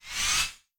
whooshForth_Far2.wav